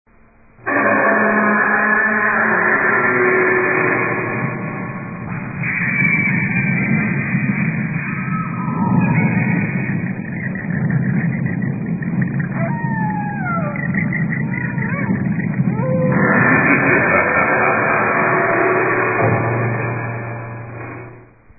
Sound Effects (Instructions: play)
Haunted House